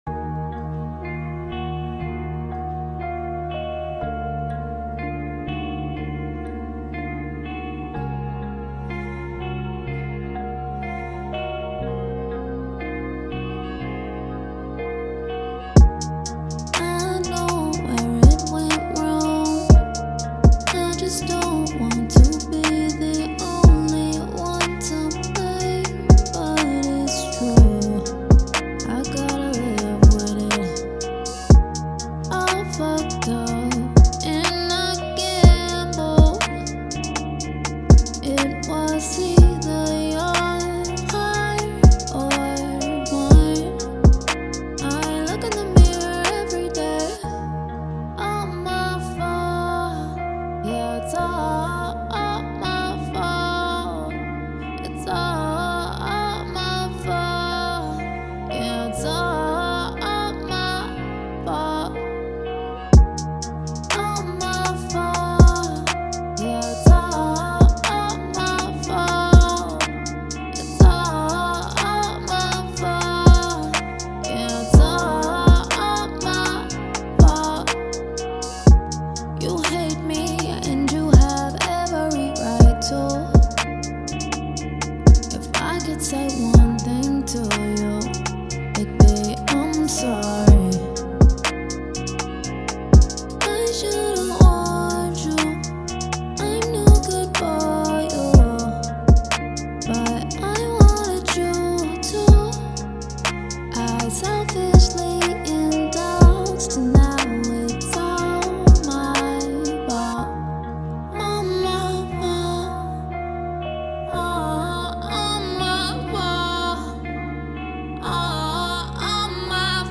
R&B
Eb Minor